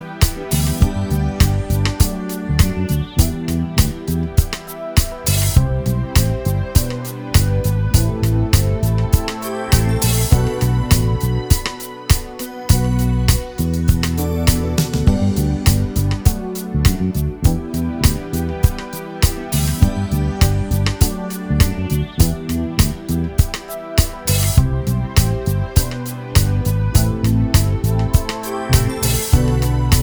No Guitar Pop (1980s) 4:22 Buy £1.50